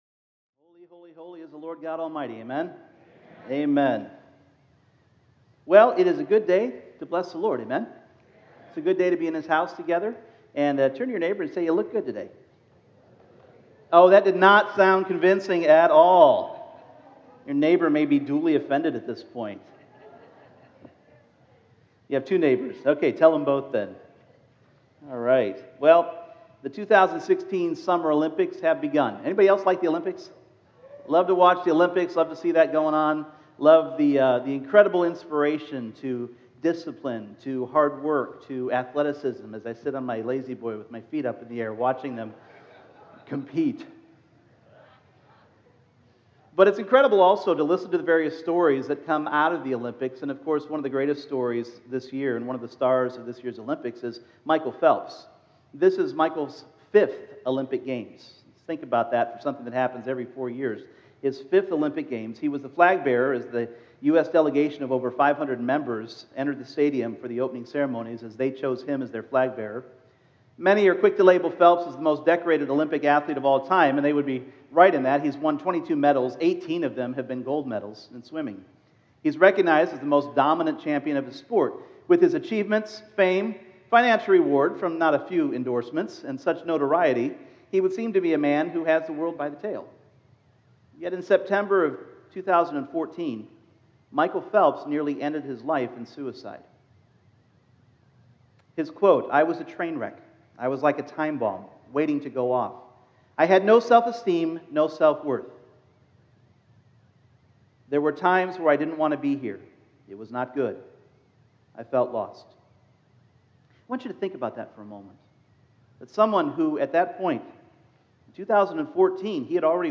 Purpose Service Type: Sunday Morning In this first message on finding God's PURPOSE for your life we are reminded that before we were born